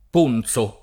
ponzo [ p 1 n Z o ]